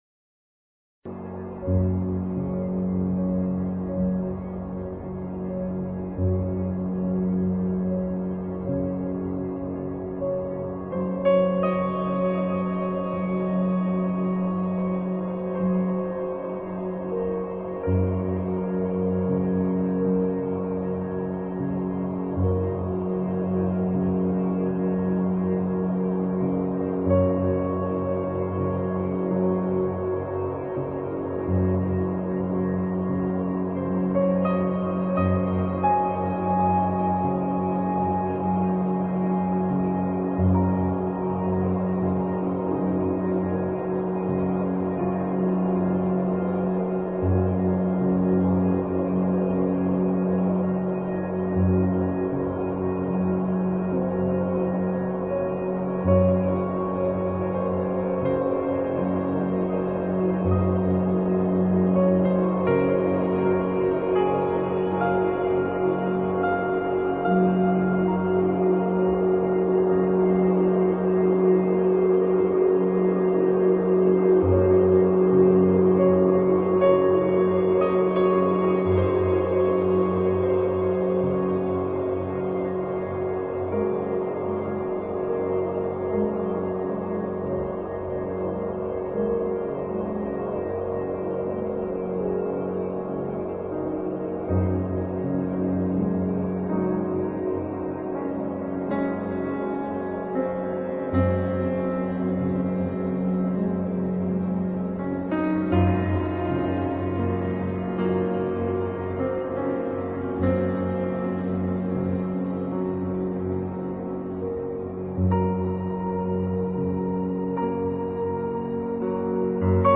Эмбиент